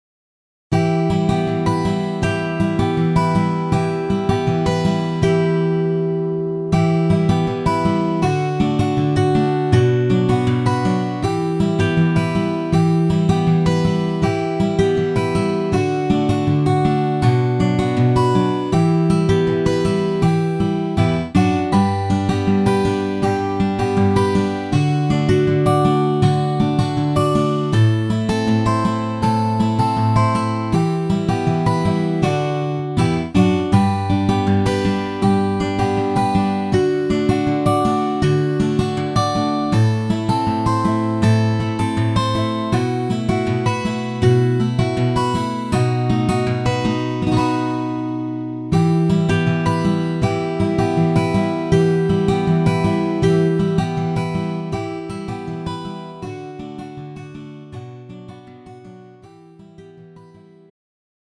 음정 원키 3:18
장르 가요 구분 Pro MR